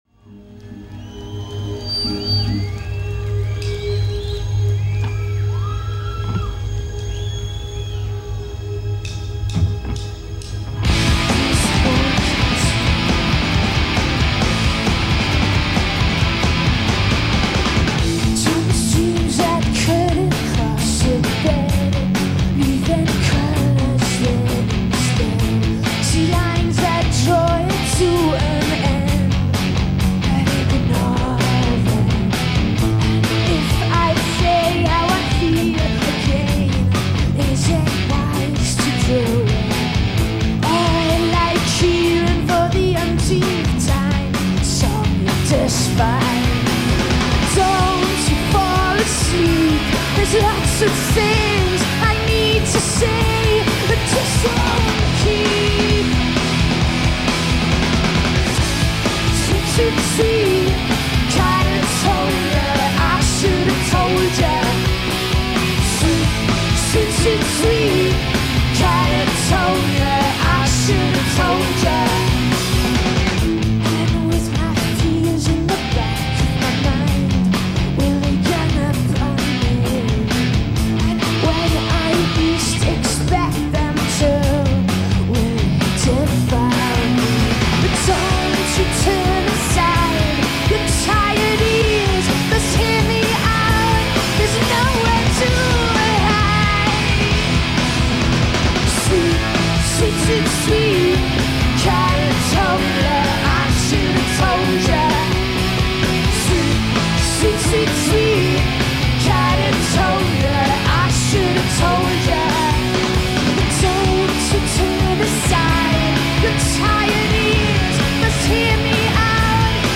live at Reading Festival 1996